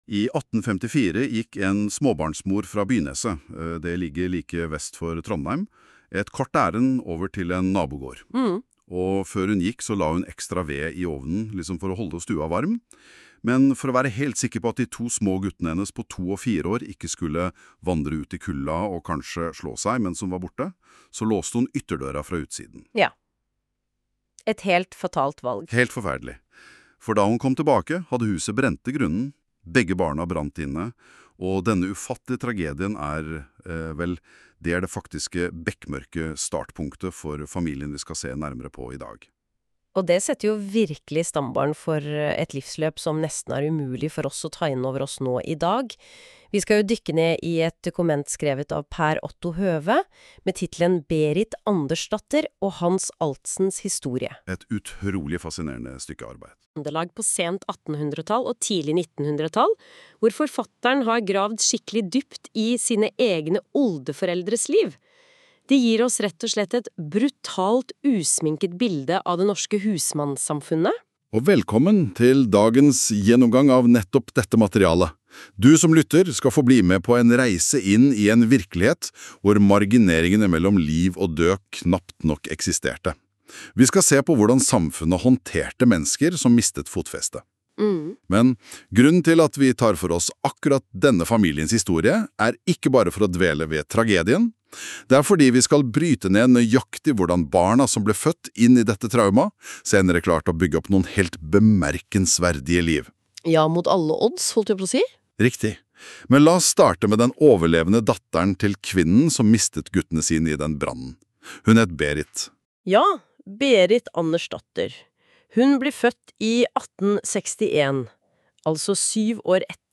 Lytt til en nær og gripende historie Generert av Gemini basert på fortellingen om mine oldeforeldre Hans og Berit.